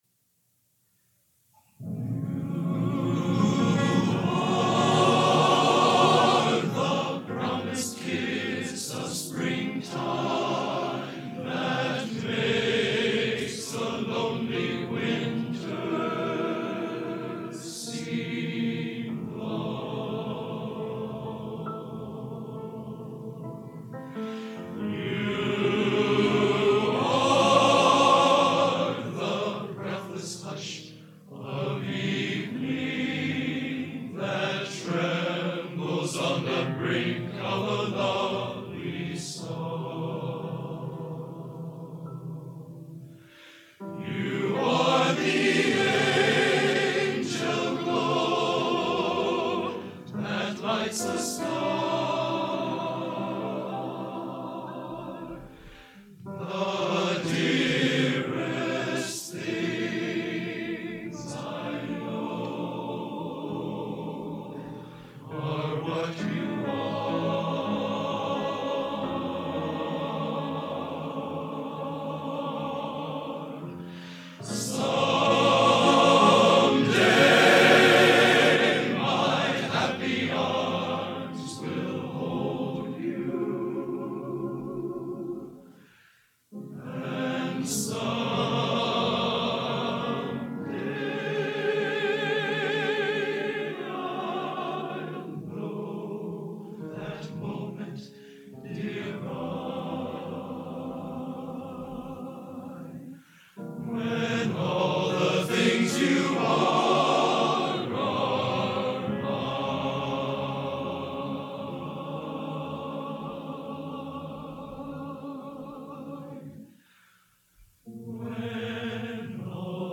Genre: Popular / Standards Schmalz | Type: End of Season